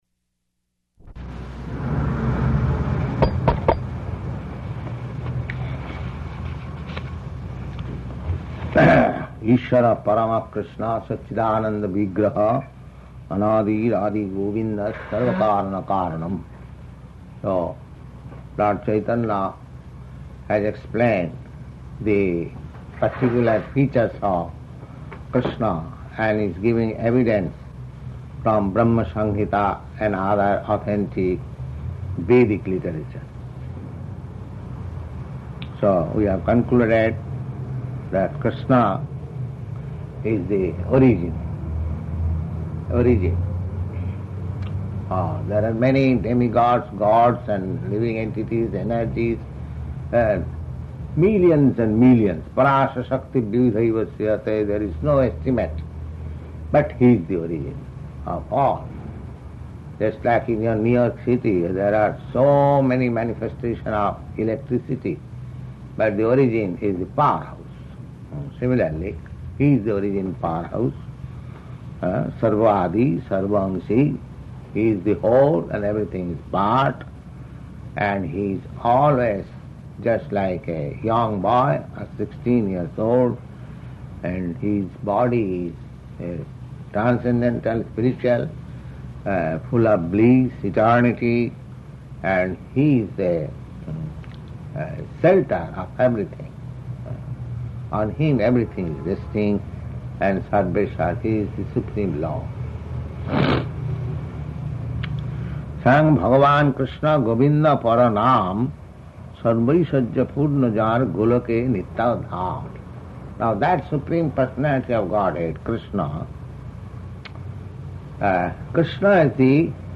Location: New York
[taps microphone]